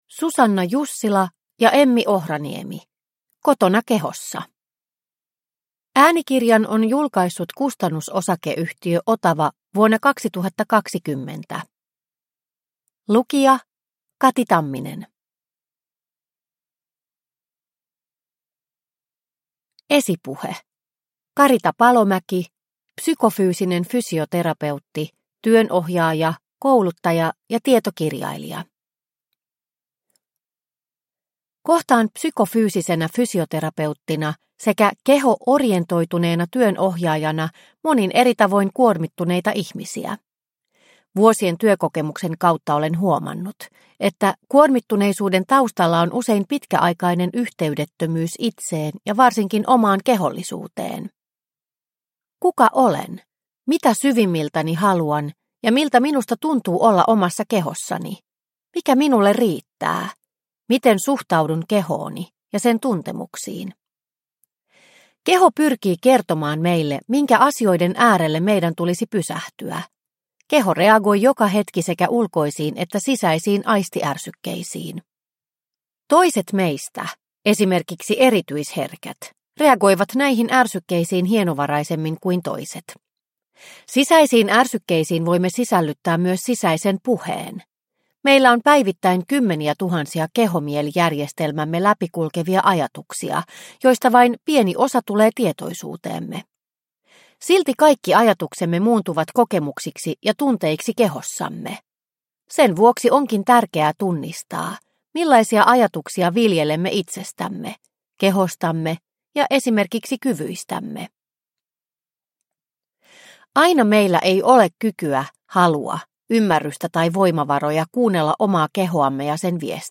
Kotona kehossa – Ljudbok – Laddas ner